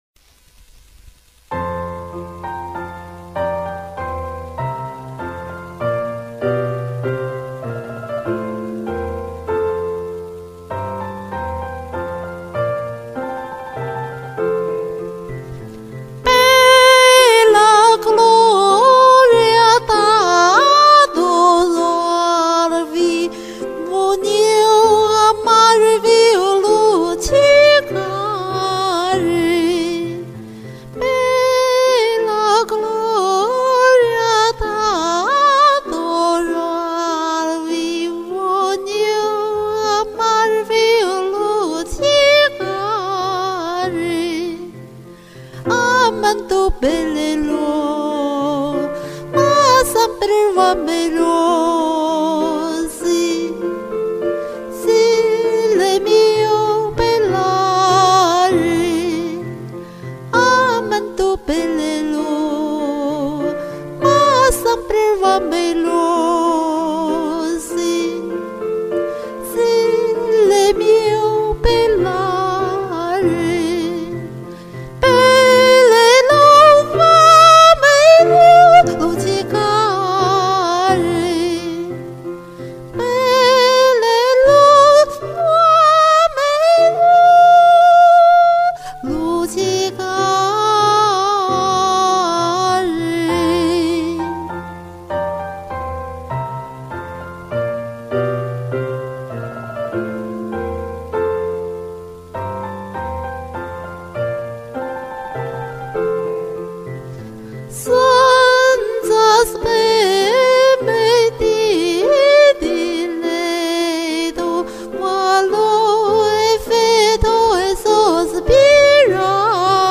《多麼幸福能讚美你》 意大利歌曲
多麼幸福能讚美你 (意大利)G.B博諾恩奇尼 曲  尚家驤 譯配
聽着意大利語很正宗啊，唱的真好，這歌也好聽，錄音好乾淨清爽。